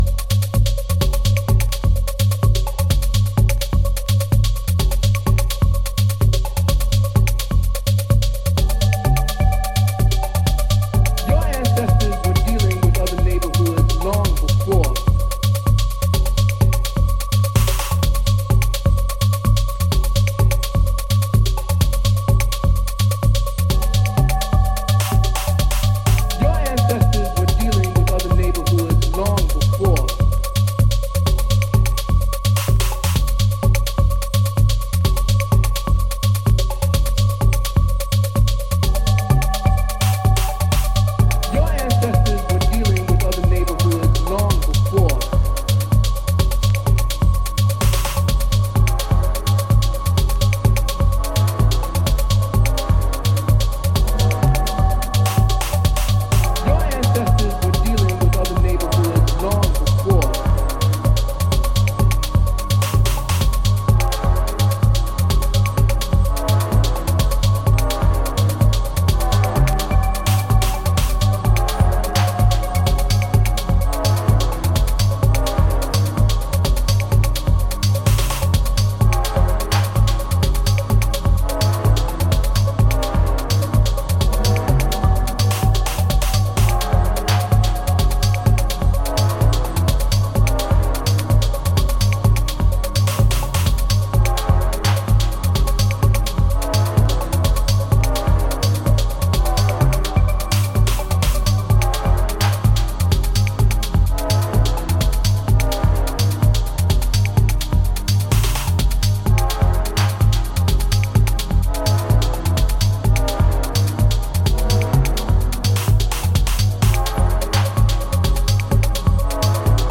インナートリップを推奨するきめ細やかなサウンドメイクが光るおすすめ曲。